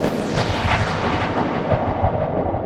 Index of /musicradar/rhythmic-inspiration-samples/90bpm